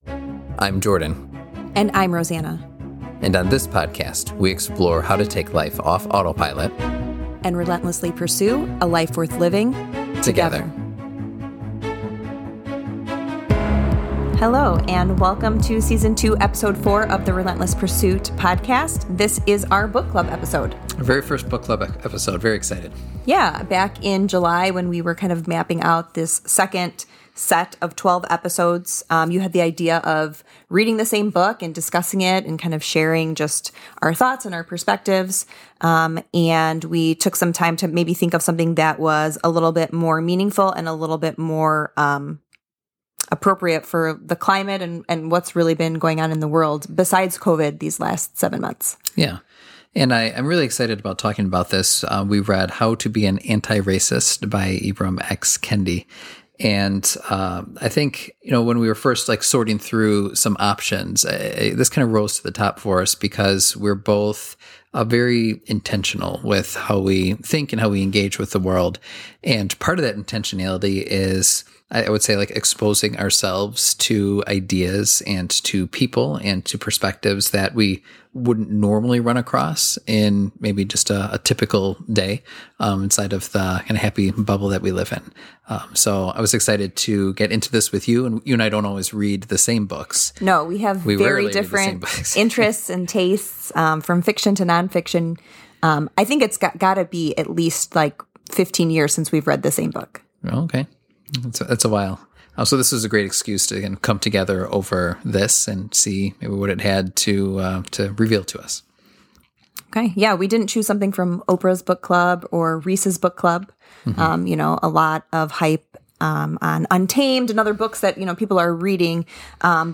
We agree to read the same book on our own, then discuss it together on the show.